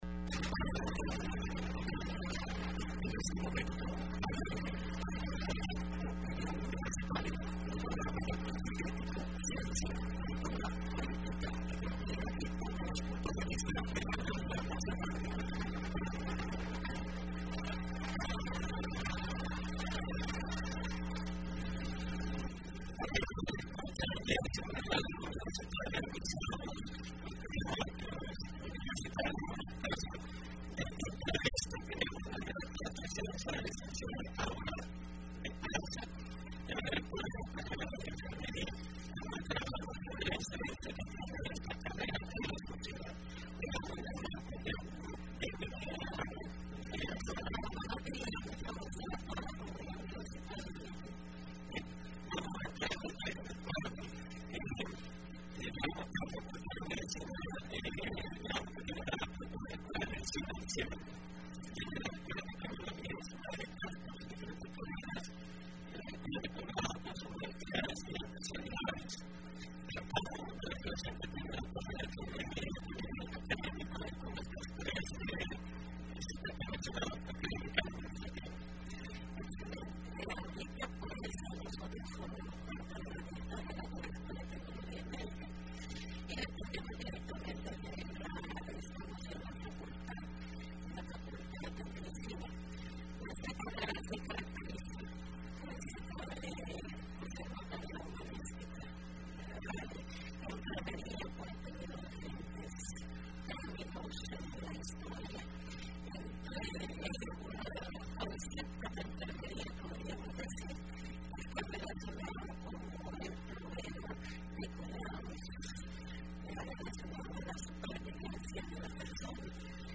Entrevista Opinión Universitaria (12 marzo 2015): Incidencia de la carrera de enfermería en la sociedad salvadoreña